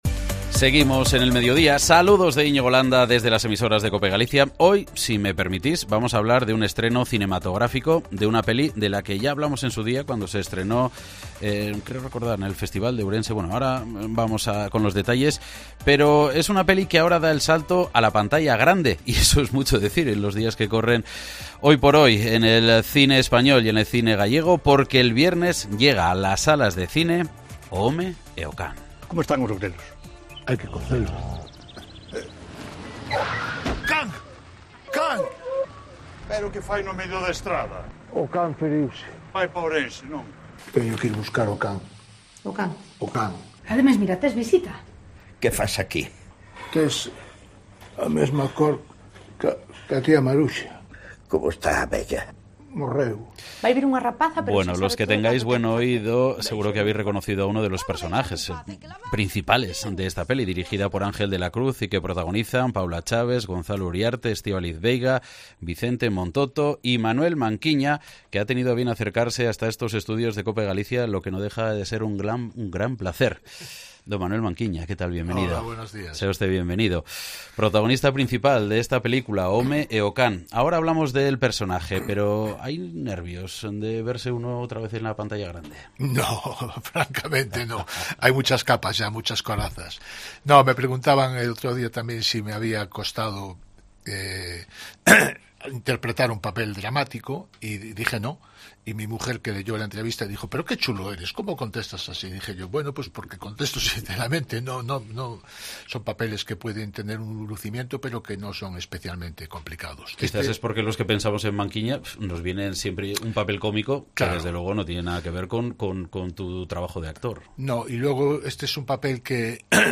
Entrevista con Manuel Manquiña pocos días antes del estreno en salas de la película "O Home e o can"